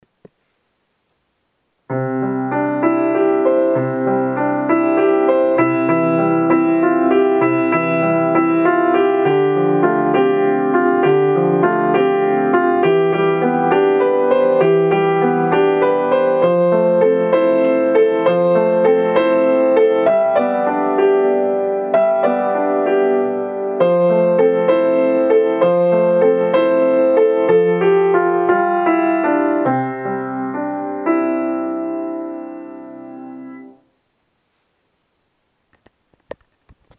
からだの外に向かって動きを作る遊び歌です。